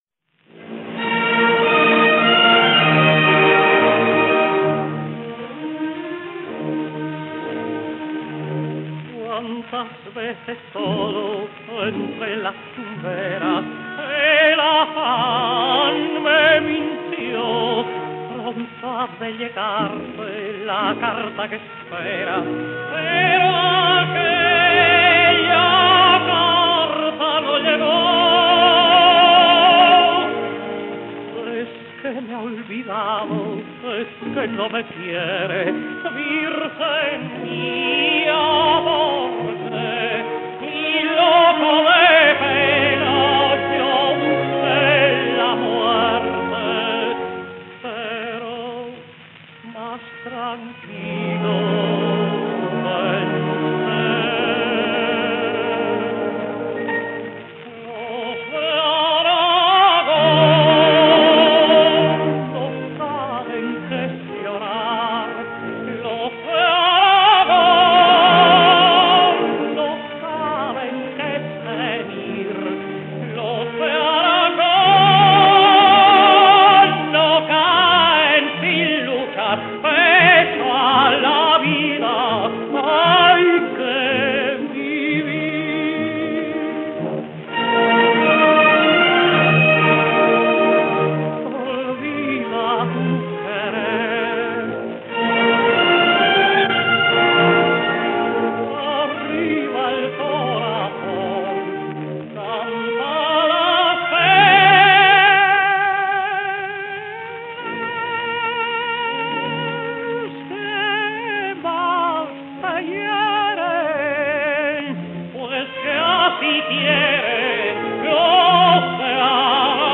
Tino Folgar sings Los de Aragón: